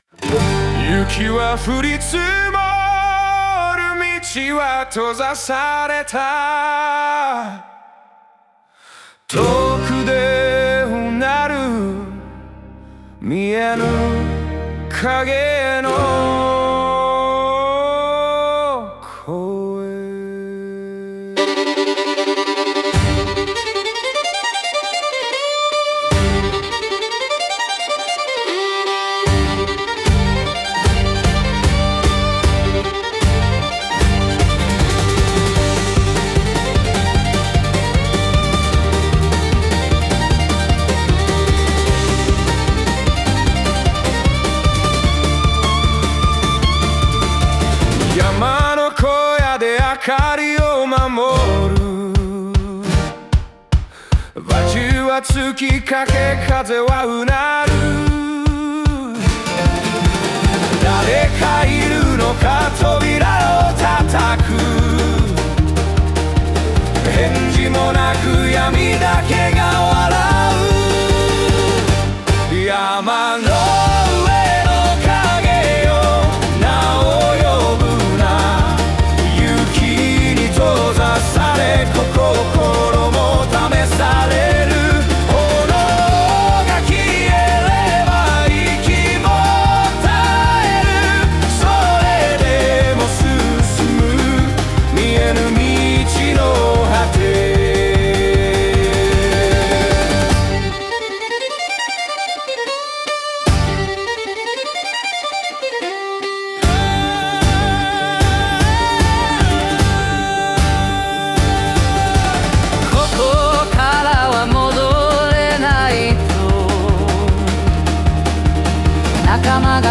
ブルーグラス調の高揚感あるリズムにより、恐怖と決意が同時に響き合う構成です。